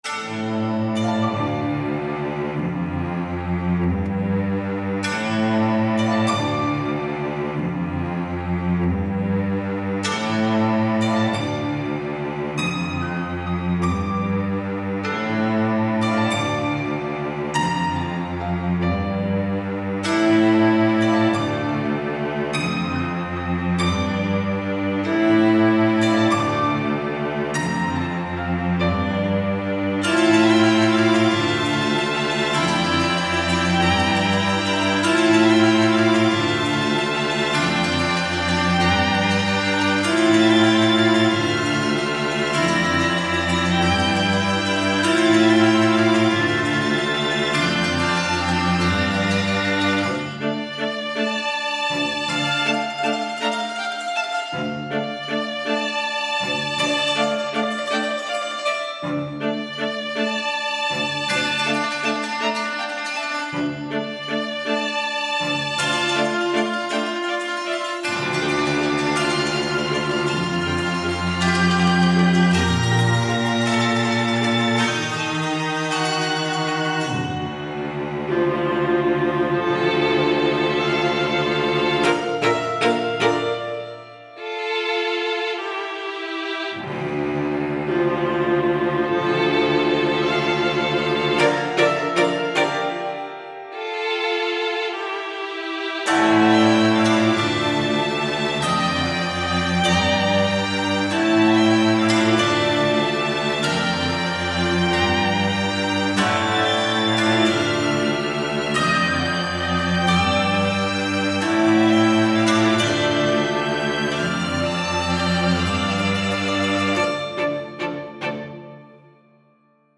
Style Style Classical
Mood Mood Mysterious
Featured Featured Strings